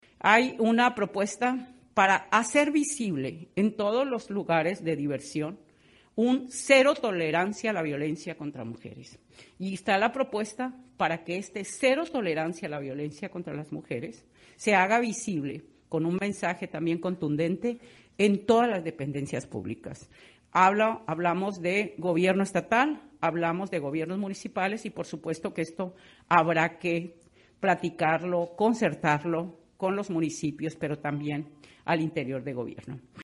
Al encabezar su primera conferencia de prensa semanal, denominada “La Semanera”, el gobernador Rubén Rocha Moya reiteró que su gobierno se distinguirá por su alto sentido social y humanista, y como prueba de ello recordó que sus primeras reuniones de trabajo fueron los encuentros que sostuvo con los colectivos de desplazados forzosos, y de búsqueda de personas desaparecidas, en cumplimiento al compromiso que hizo en el sentido de que lo primero que haría como gobernador, sería recibirlos para escucharlos y diseñar una política de Estado en esta materia.
Primeramente, la secretaria de las Mujeres, Teresa Guerra, señaló que si bien el delito del feminicidio en Sinaloa ha registrado una reducción paulatina desde el 2017, la violencia contra las mujeres al interior del hogar se ha multiplicado de manera considerable, con incrementos de hasta un 45 por ciento en sus estadísticas.